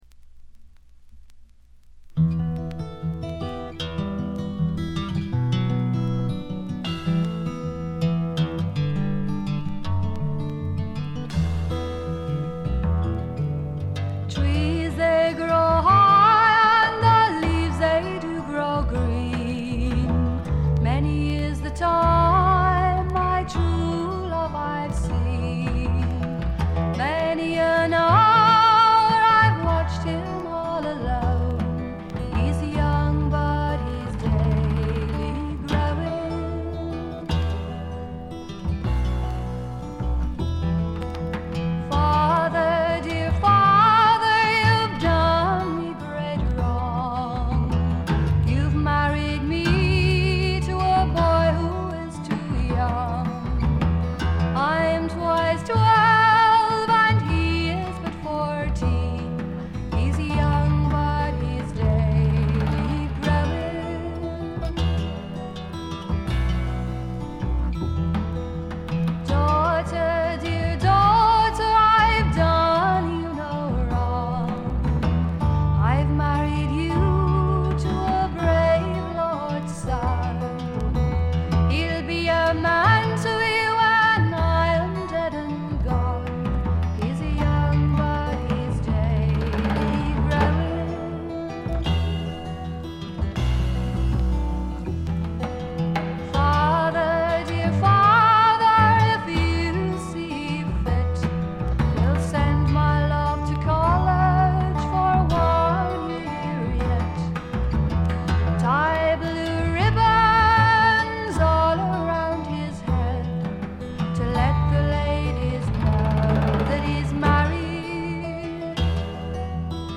他はB面で一部軽い周回ノイズ、C面D面のスタジオ面は軽微なチリプチ少々で良好。
試聴曲は現品からの取り込み音源です。
Sides 3 and 4, Recorded at IBC Studios,London, August 1968.